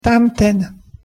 pronunciation_sk_tamten.mp3